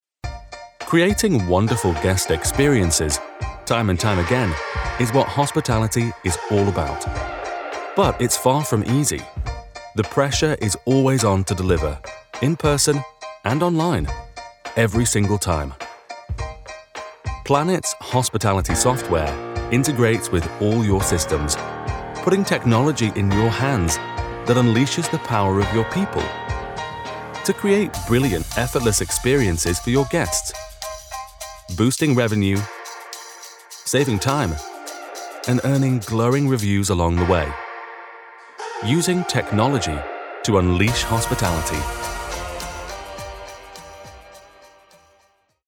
His voice is warm, assured, friendly, and authentic.
international english
explainer video
ExplainerReelPlanet.mp3